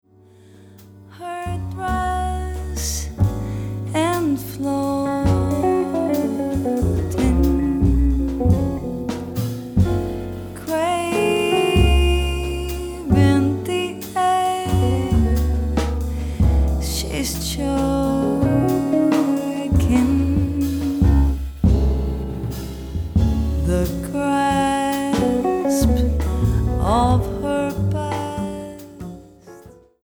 vocals
guitar
piano/Fender Rhodes
bass
drums